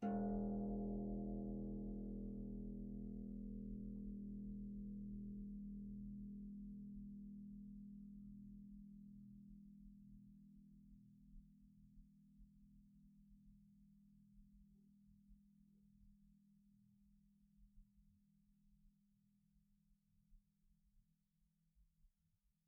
KSHarp_E1_f.wav